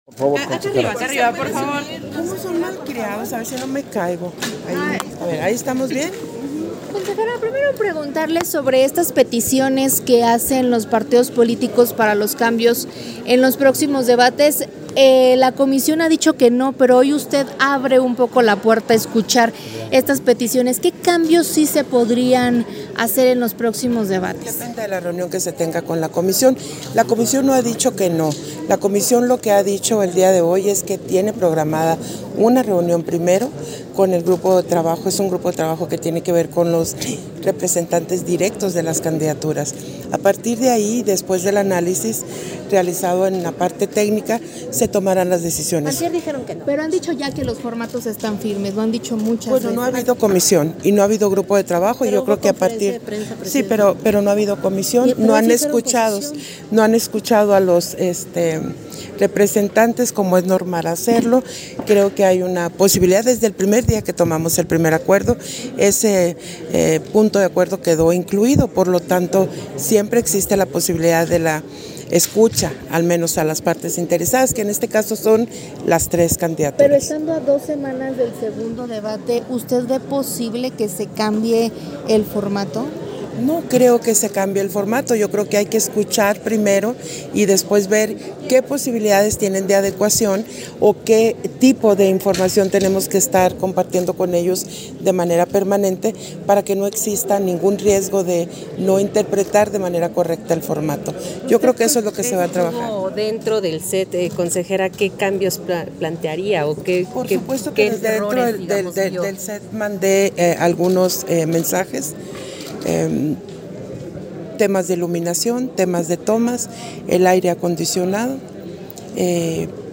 110424_AUDIO_ENTREVISTA-CONSEJERA-PDTA.-TADDEI-POSTERIOR-A-LA-SESIÓN-EXTRAORDINARIA
Versión estenográfica de la entrevista a Guadalupe Taddei, posterior a la Sesión Extraordinaria del Consejo General